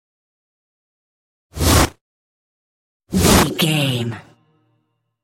Flying logo whoosh x2
Sound Effects
Atonal
futuristic
intense
whoosh